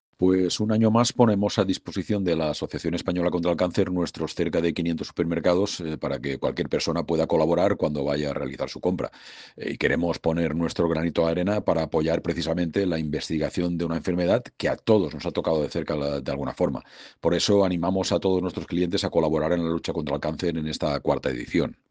Sound bite